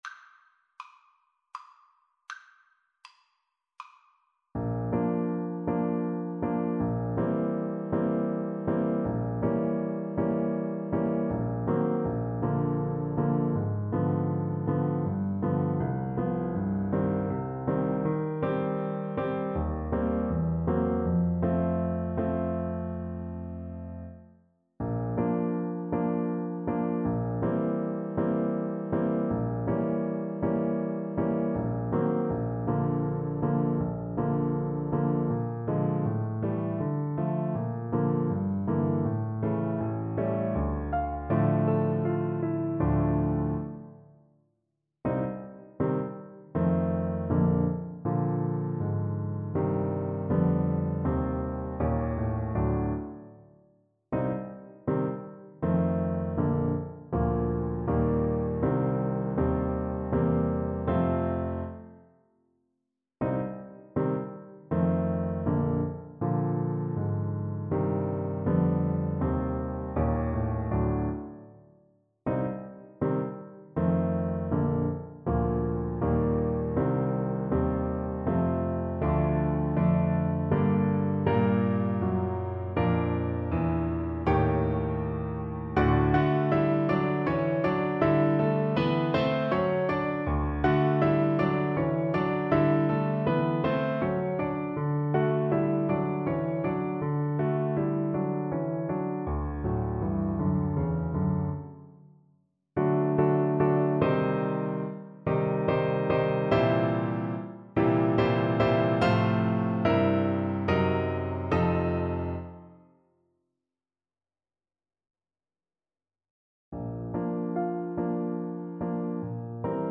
Alto Saxophone
Moderato con moto =80
3/4 (View more 3/4 Music)
Classical (View more Classical Saxophone Music)
Saxophone pieces in Bb major